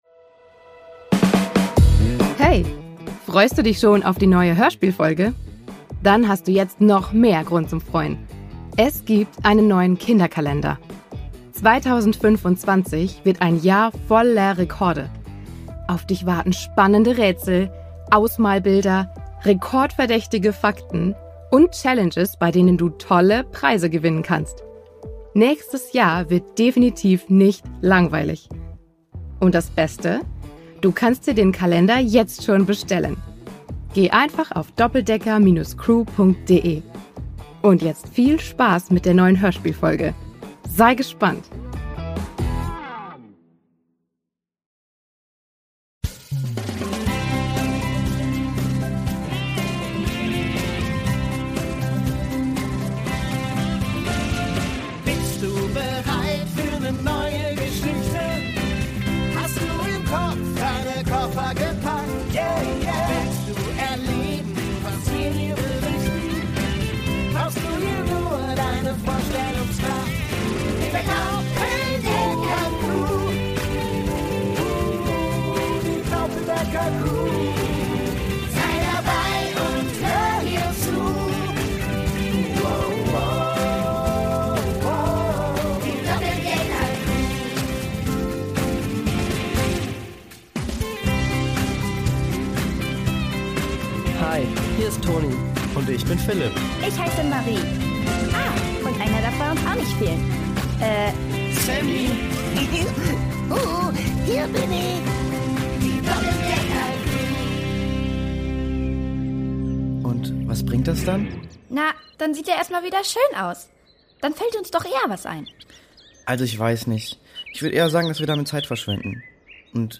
Brasilien 2: Hochzeit mit Hindernissen | Die Doppeldecker Crew | Hörspiel für Kinder (Hörbuch) ~ Die Doppeldecker Crew | Hörspiel für Kinder (Hörbuch) Podcast